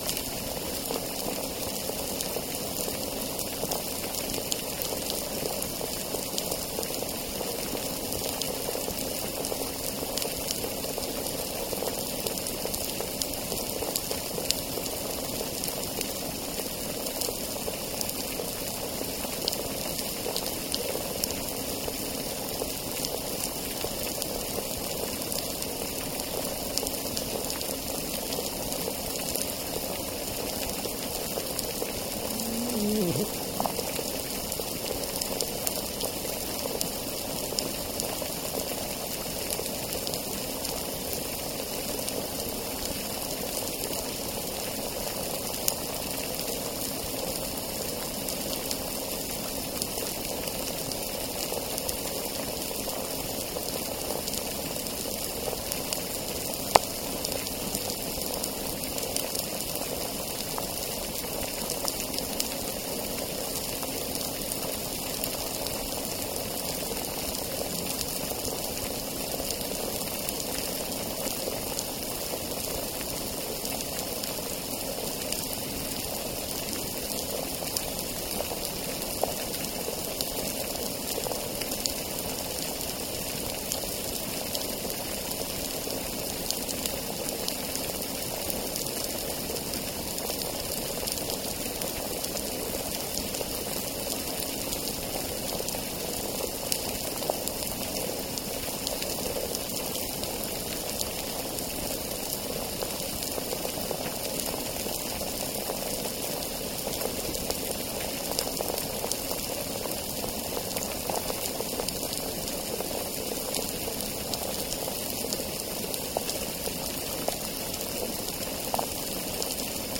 Demonstration soundscapes
biophony